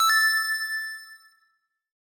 sound2_button.ogg